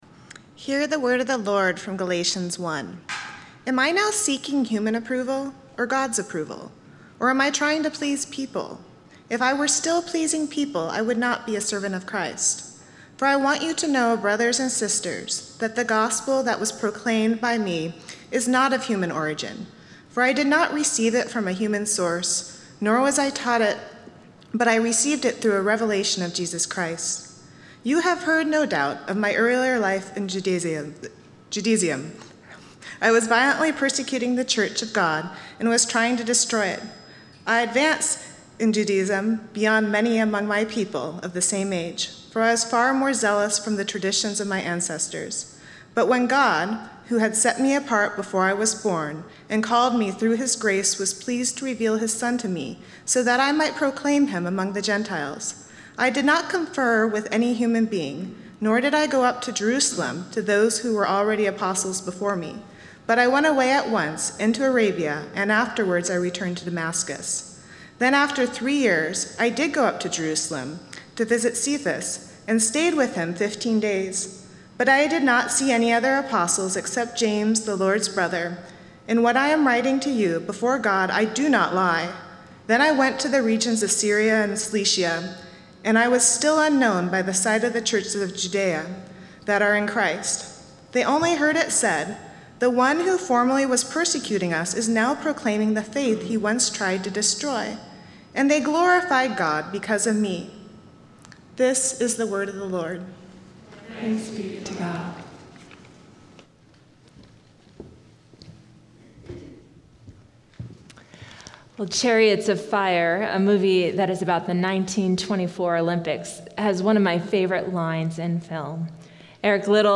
Passage: Galatians 1:10-24 Service Type: Sunday Morning